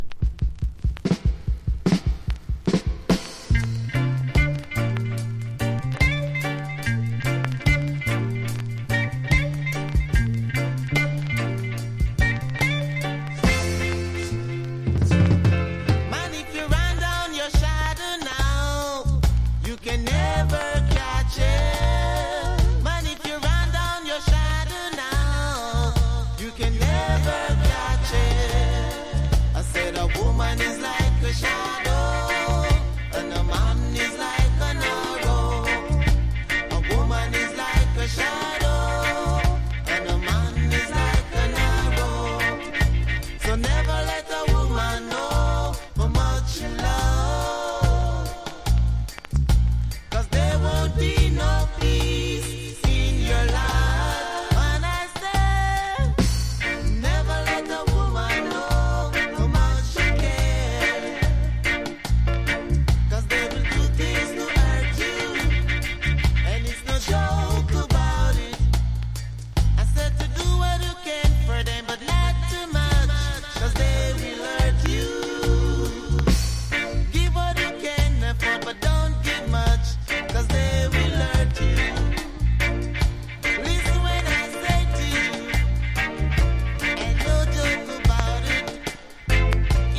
1. REGGAE >
レゲエ・ヴォーカル・グループ